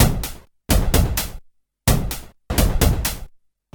VEH1 Fx Loops 128 BPM
VEH1 FX Loop - 02.wav